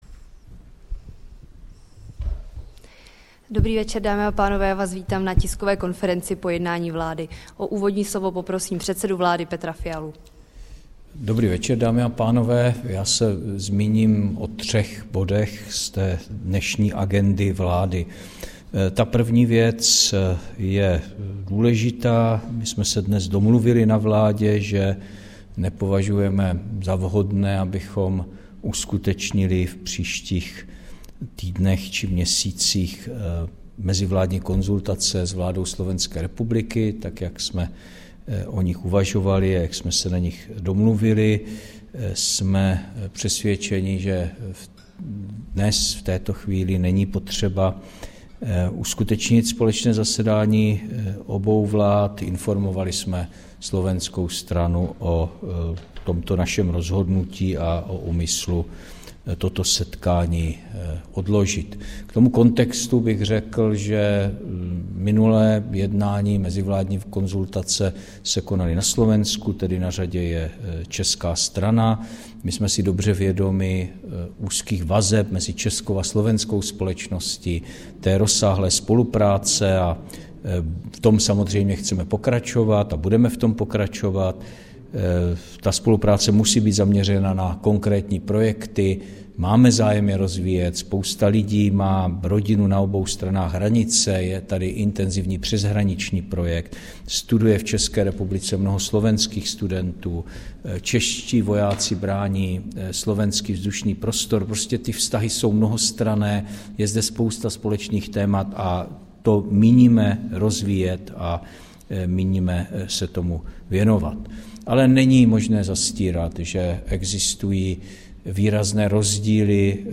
Tisková konference po jednání vlády, 6. března 2024